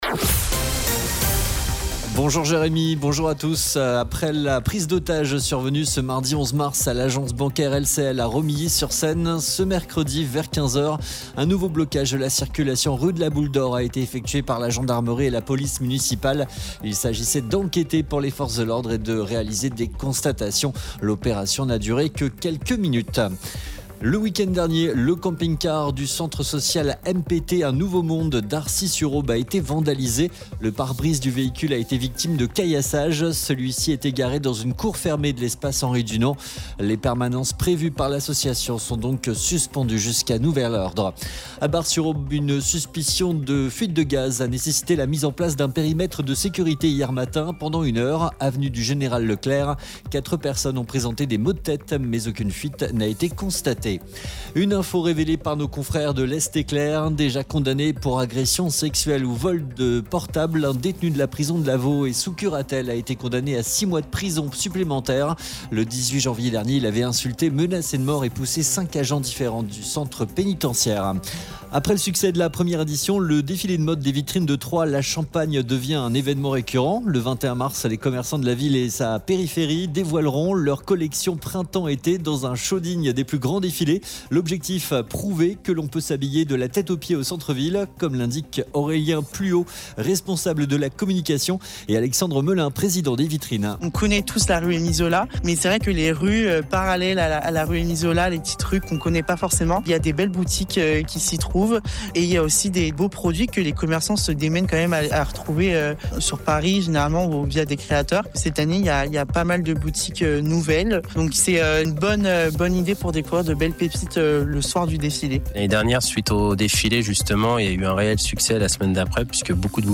Journal Edition Régionale 8h30